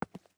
ES_Footsteps Concrete 5.wav